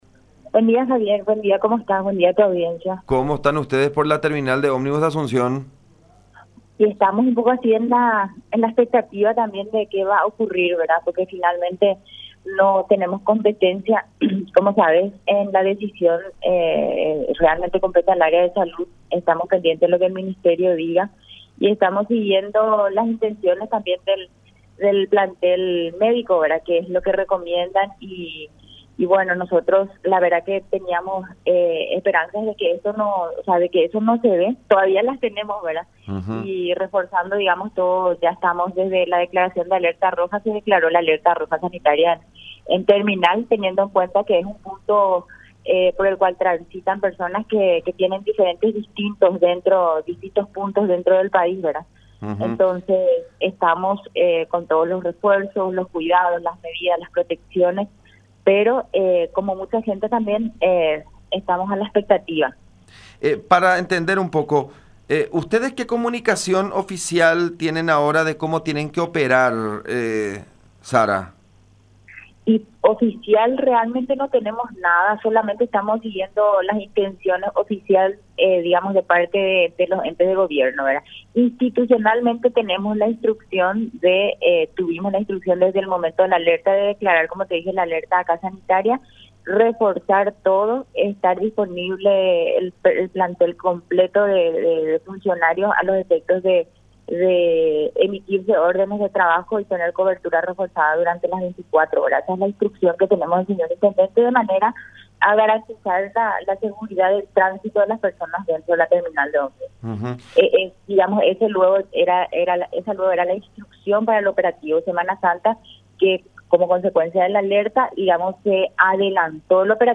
en diálogo con La Unión R800 AM.